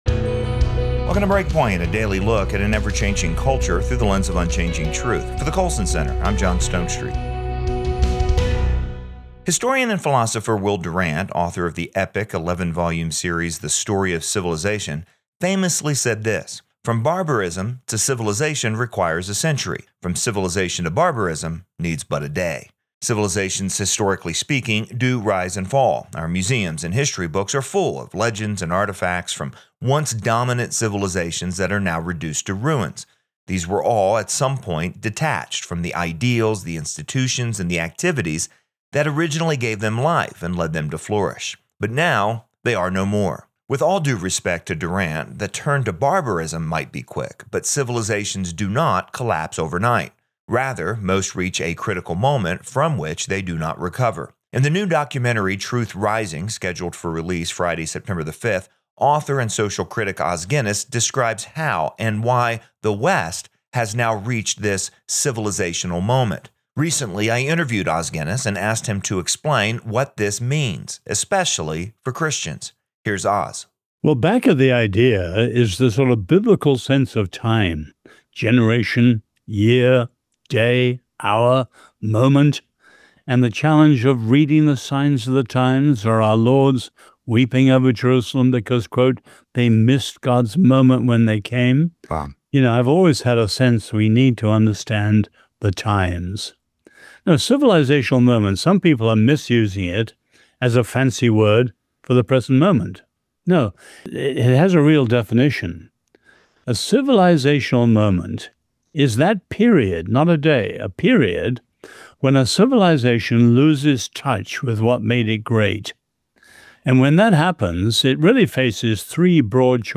Historian and philosopher Oz Guinness explains the concept of a civilizational moment, where a civilization loses touch with its core values and faces a choice between renewal, replacement, or decline. He argues that the West is currently at this moment, and that Christians have a crucial role to play in shaping the future.